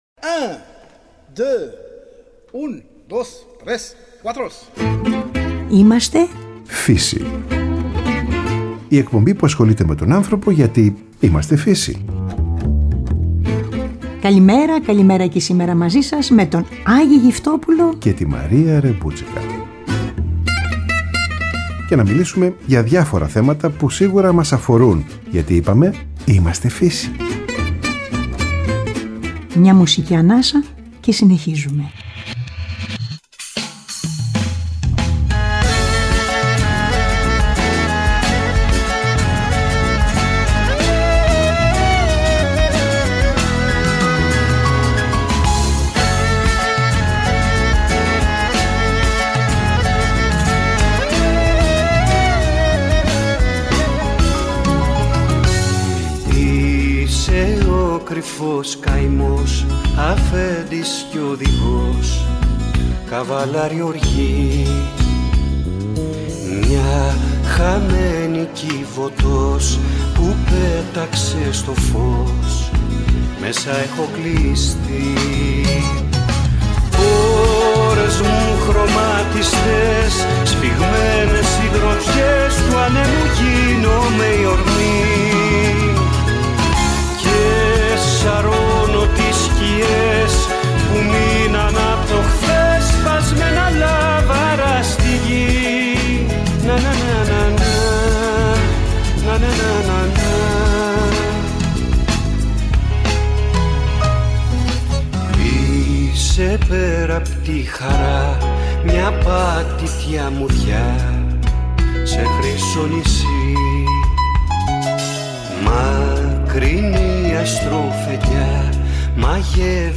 Τηλεφωνική επικοινωνία